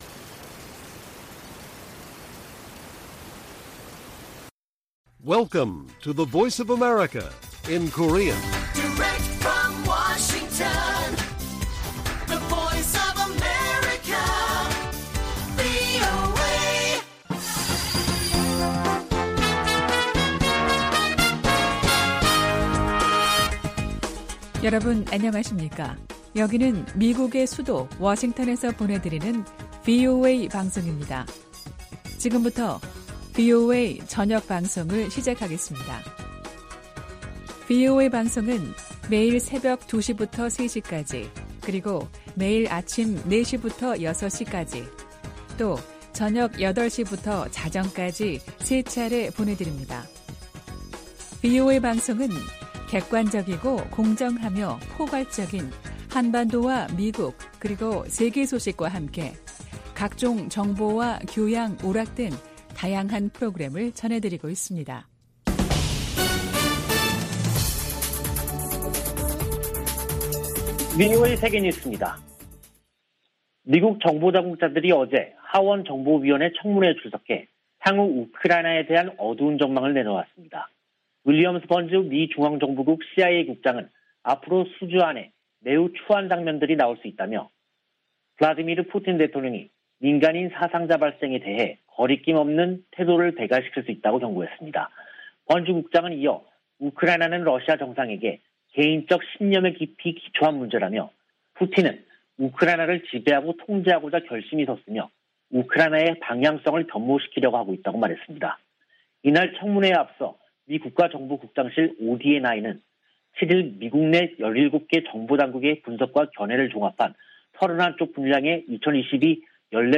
VOA 한국어 간판 뉴스 프로그램 '뉴스 투데이', 2022년 3월 9일 1부 방송입니다. 북한이 미국과 동맹국을 겨냥해 핵과 재래식 능력을 지속적으로 확장하고 있다고 미 국가정보국장이 평가했습니다. 북한이 신형 ICBM을 조만간 시험발사할 수 있다는 미군 고위 당국자의 전망이 나왔습니다. 한국 20대 대통령 선거가 9일 실시됐습니다.